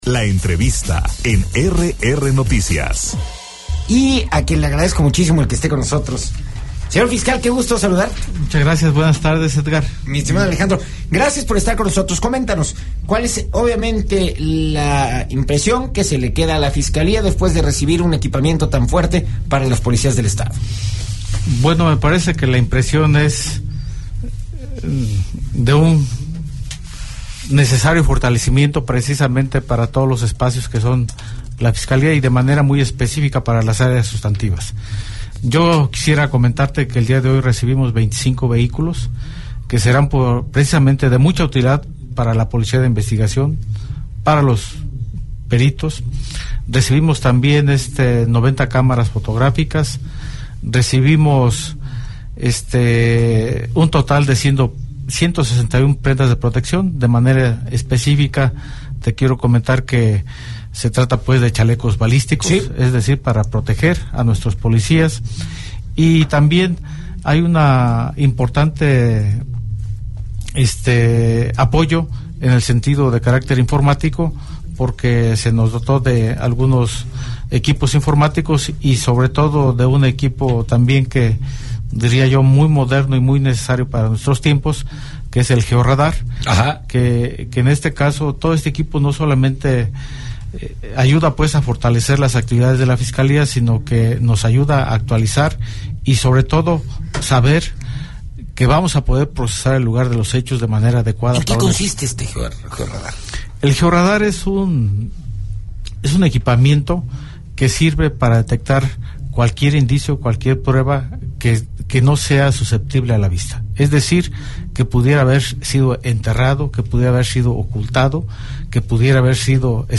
EntrevistasMultimediaPodcast
Entrevista con el Fiscal General del Estado de Querétaro, Alejandro Echeverría Cornejo